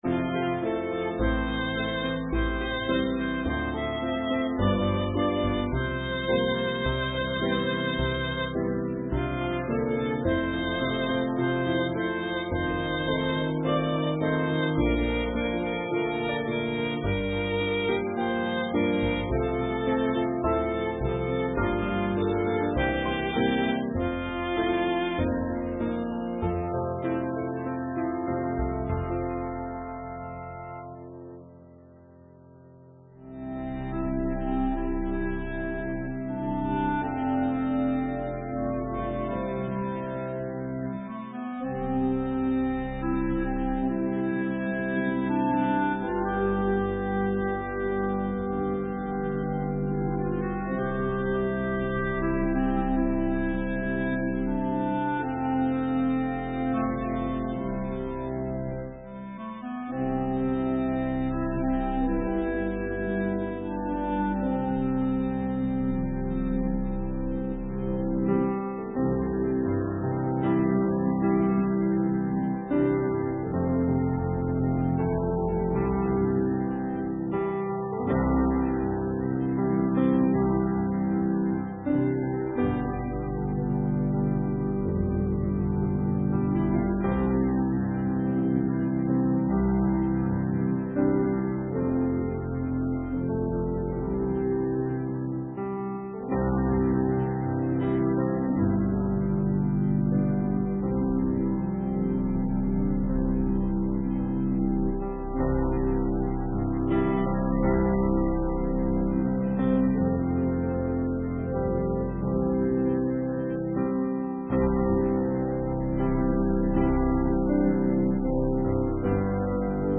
Note: The prelude begins 15 minutes into the video and the audio file
Worship Service